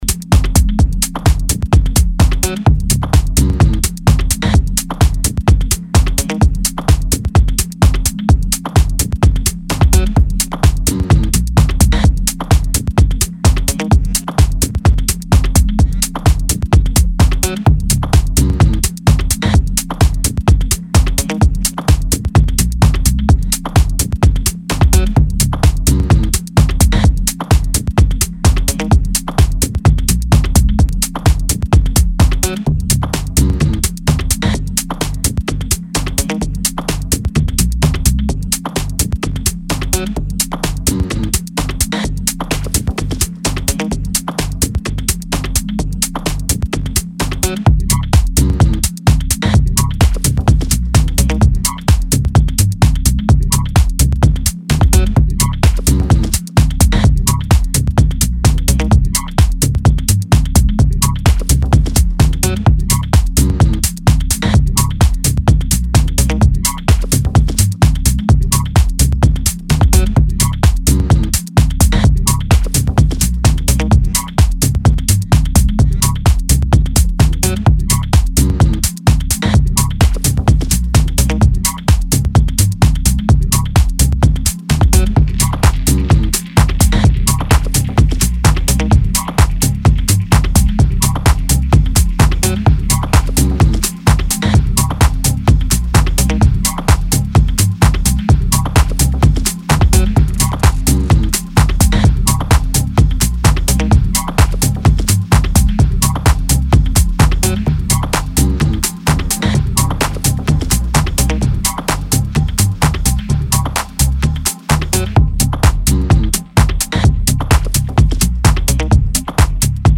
minimal house version